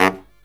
LOHITSAX12-R.wav